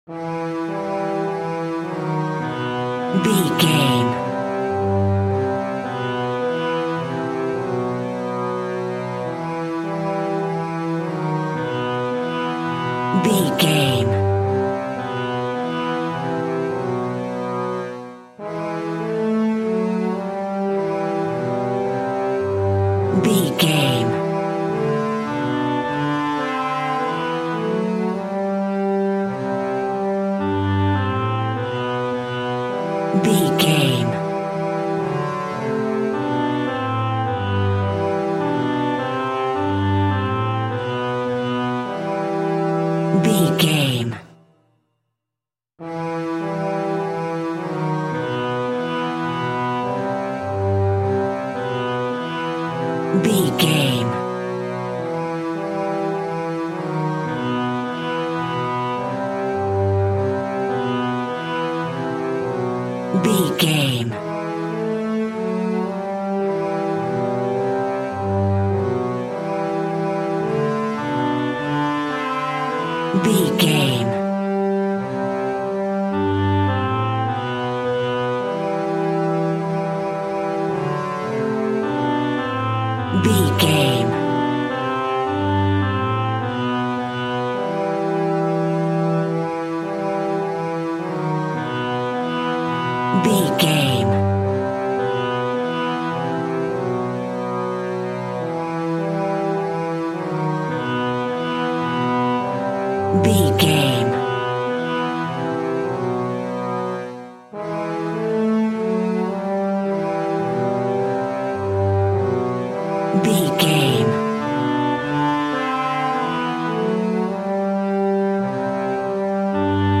Aeolian/Minor
scary
tension
ominous
dark
suspense
haunting
eerie
strings
synthesiser
brass
woodwind
percussion
mysterious